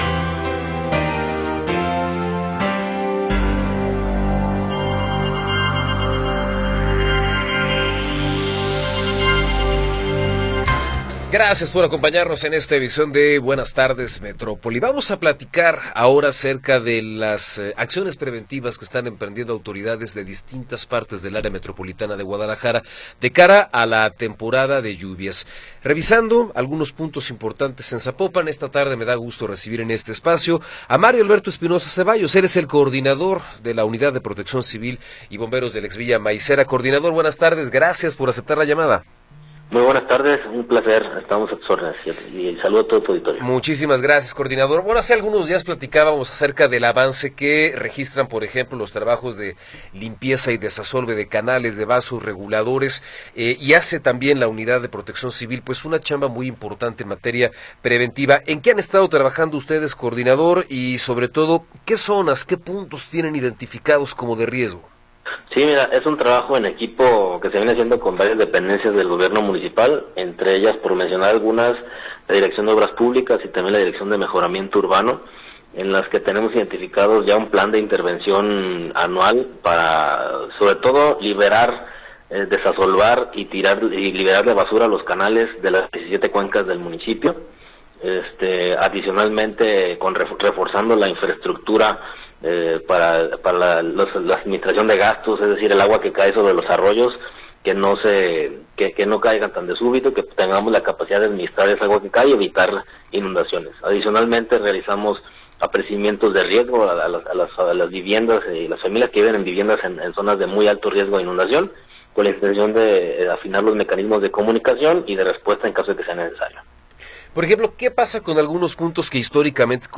Entrevista con Mario Alberto Espinosa Ceballos